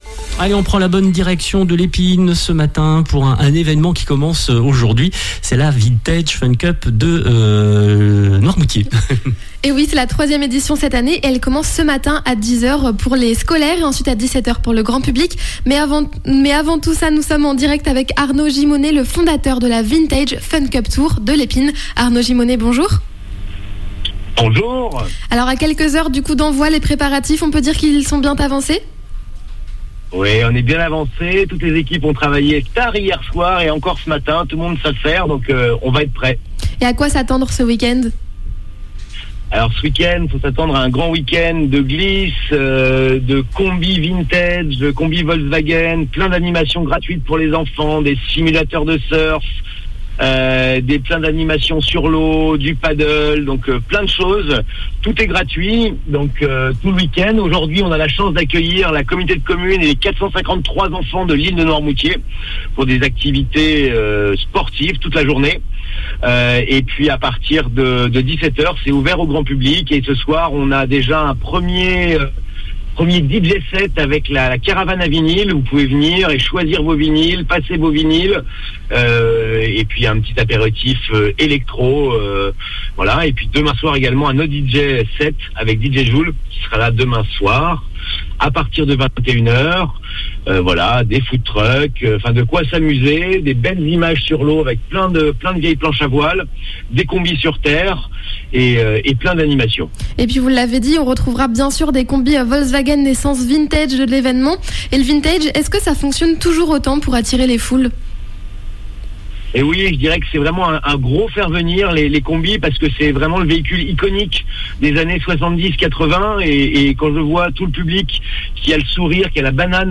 Les Reportages de NOV FM
Nous étions en direct ave lui ce matin, par téléphone.